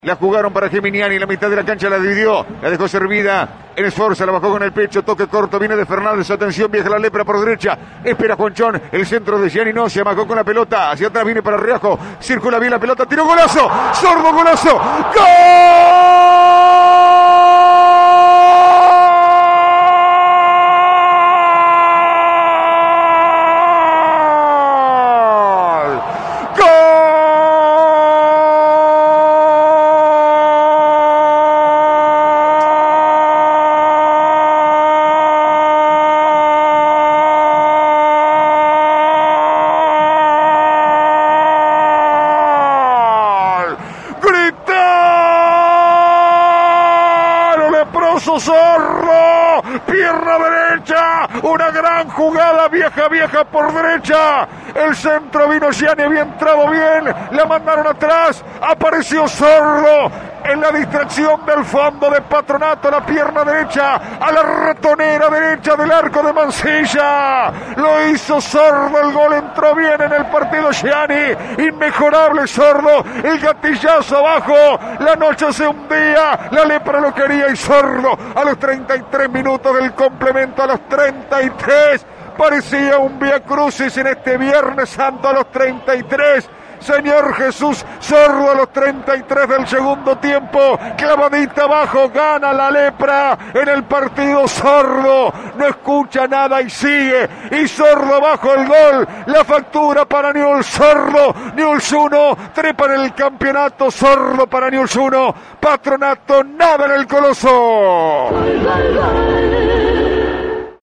Gol